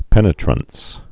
(pĕnĭ-trəns)